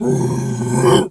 Animal_Impersonations
bear_roar_06.wav